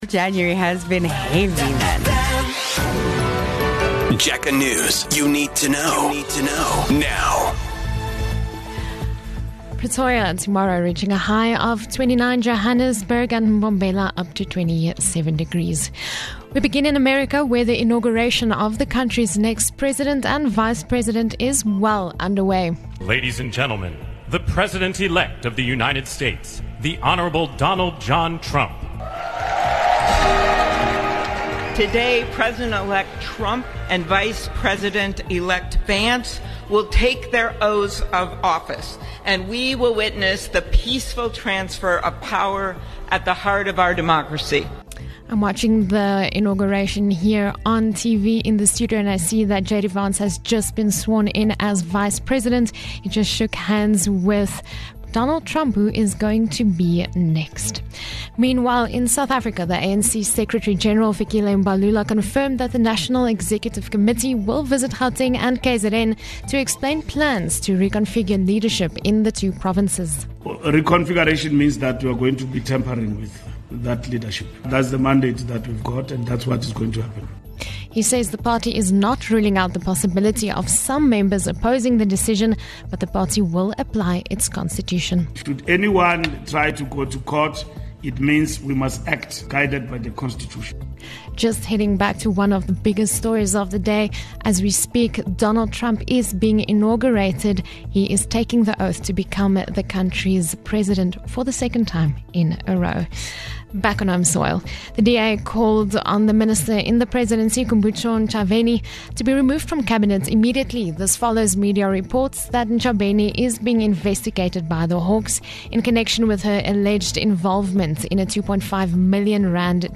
Here's your latest Jacaranda FM News bulletin.